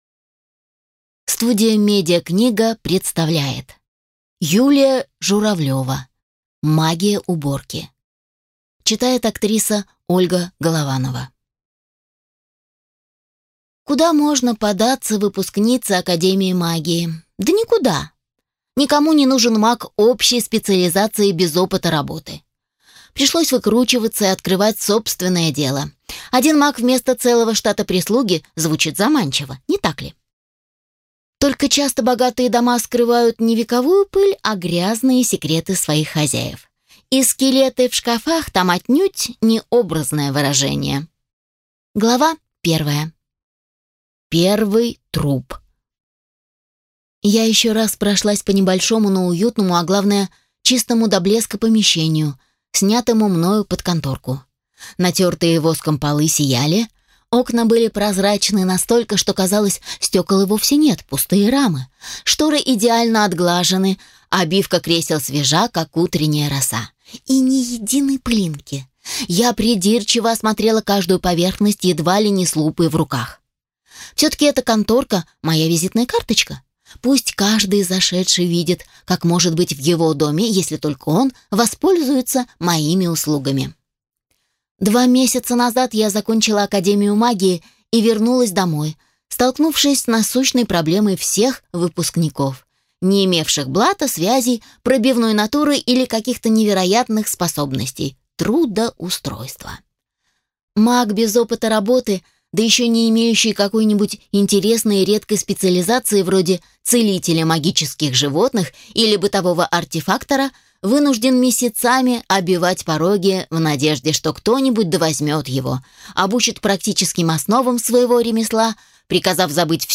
Аудиокнига Магия уборки | Библиотека аудиокниг